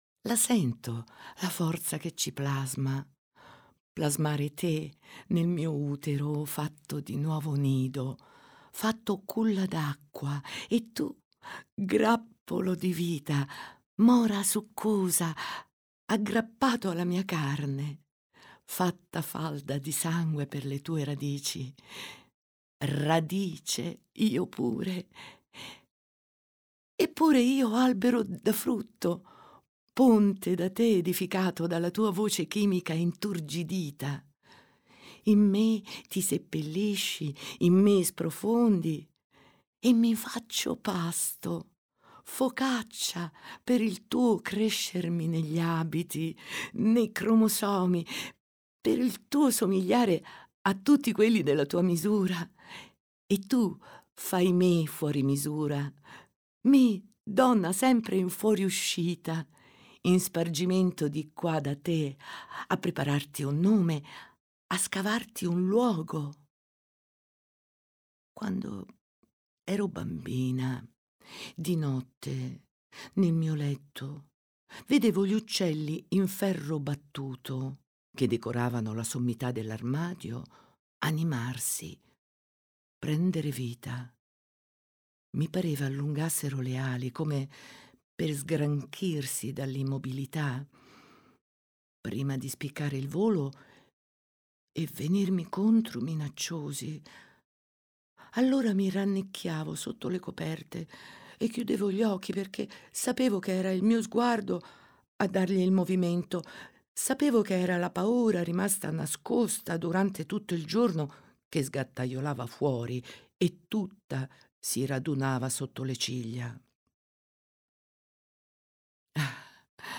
Madre Voce recitante